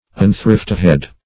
Search Result for " unthriftihead" : The Collaborative International Dictionary of English v.0.48: Unthriftihead \Un*thrift"i*head\, Unthriftihood \Un*thrift"i*hood\, n. Unthriftiness.